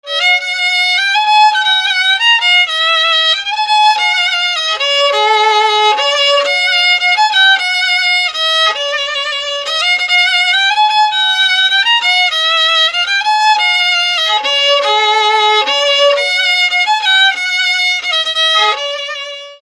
Dallampélda: Hangszeres felvétel
Erdély - Alsó-Fehér vm. - Nagymedvés
hegedű Műfaj: Névnapköszöntő Gyűjtő